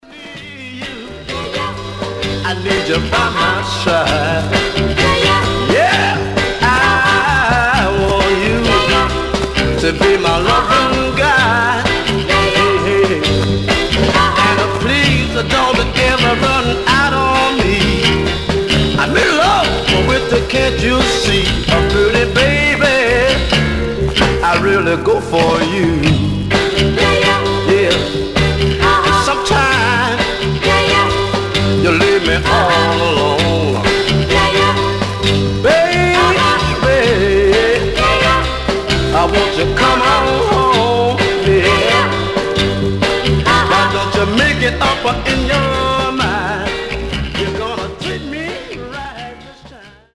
Some surface noise/wear
Mono
Soul